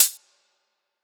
ClosedHH MadFlavor 8.wav